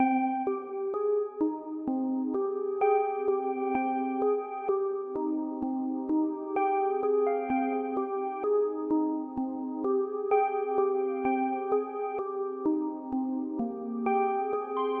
贝尔环路
描述：带一点反向效果的铃铛圈
Tag: 128 bpm Rap Loops Bells Loops 2.52 MB wav Key : D FL Studio